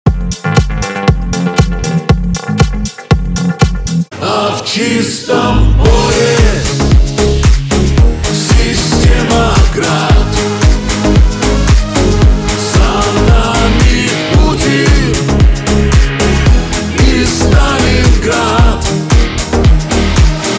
• Качество: 320, Stereo
мужской голос
патриотические
цикличные